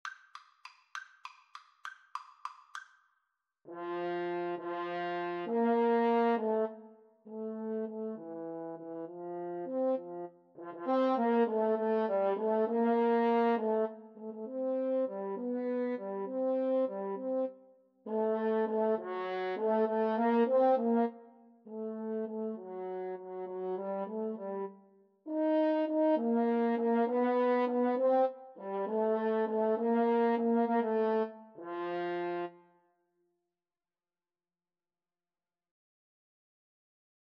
3/8 (View more 3/8 Music)
Classical (View more Classical French Horn Duet Music)